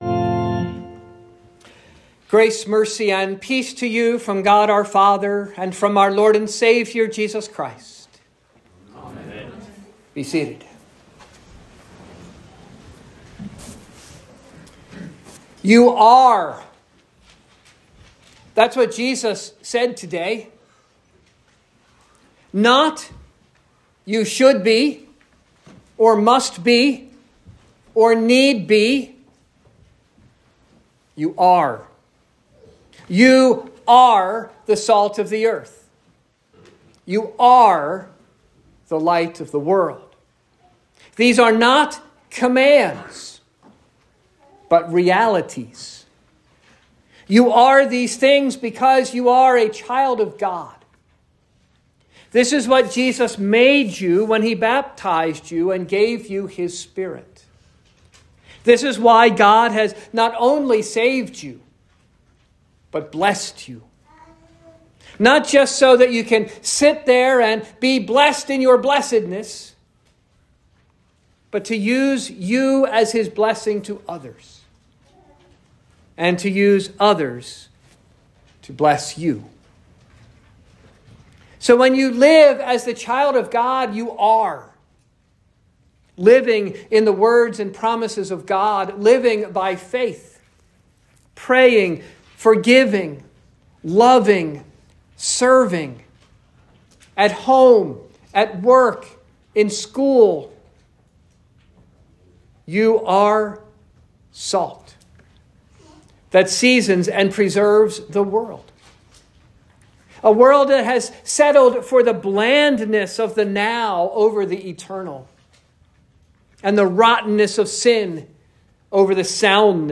Sermon for the Fifth Sunday after Epiphany